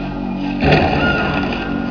crowlaugh.wav